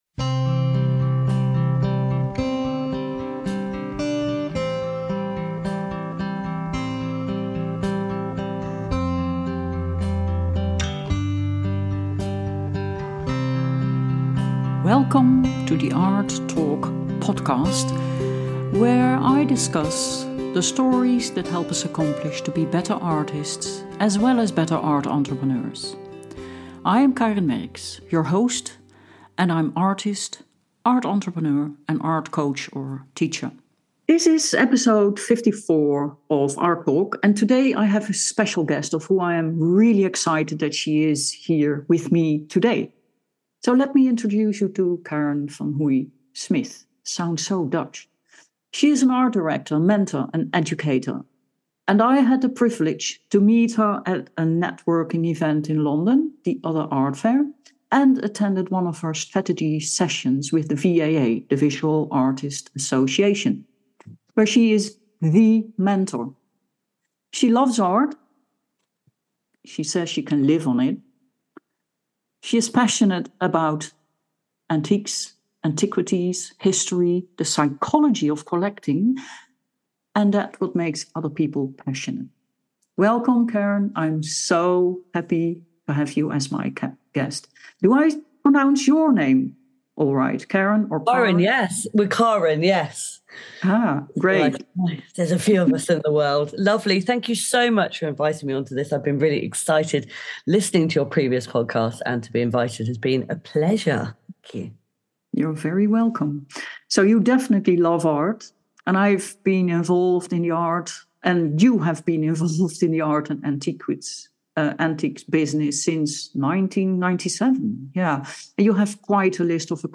A Remarkable Interview